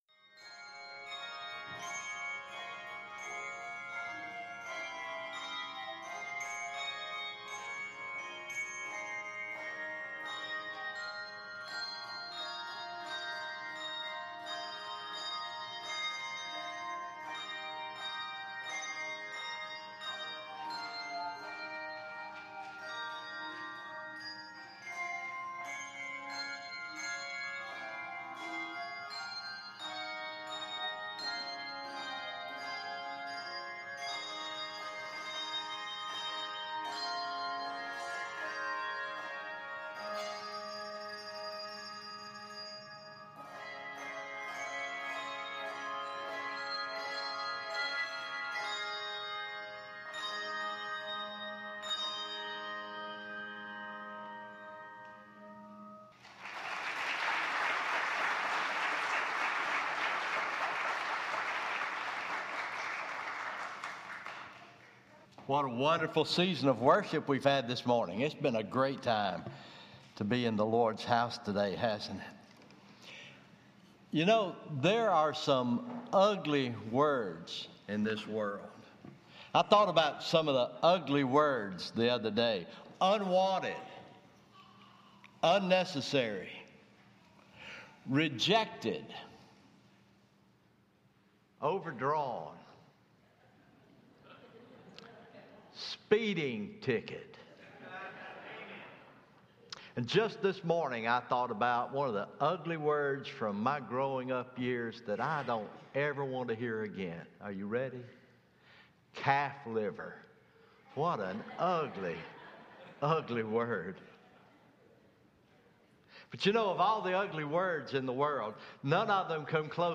Easter Morning Worship